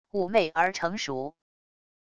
妩媚而成熟wav音频